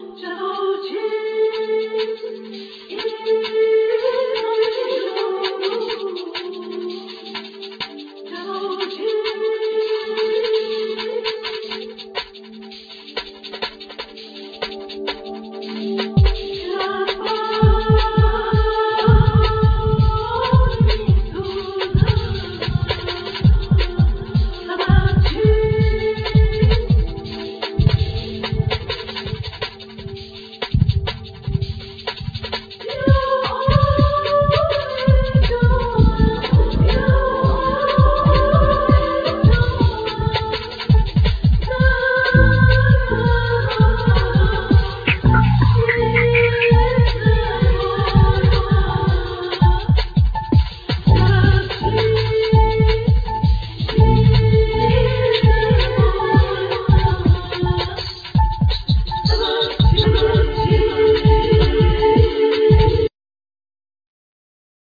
Vocals
Tabla
Tar, Setar, Kamanche, Ney